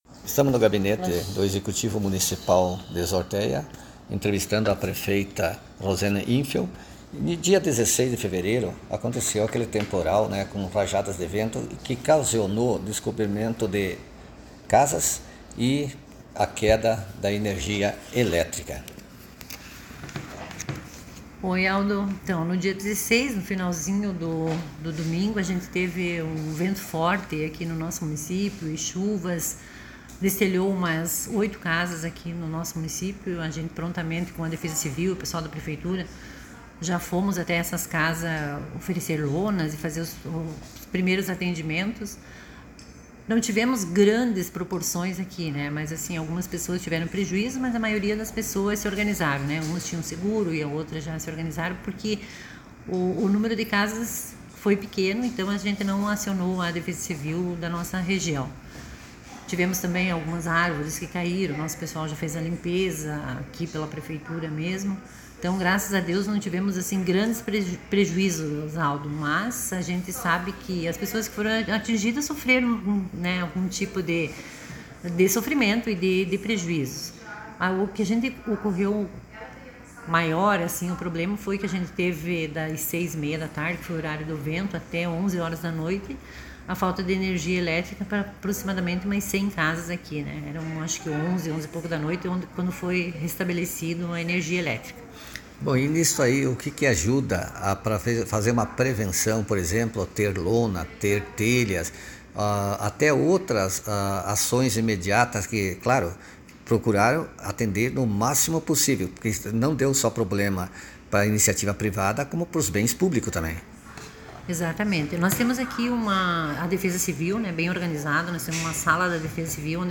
Áudio_prefeita_de_Zortéa,_Rosane_Infeld..mp3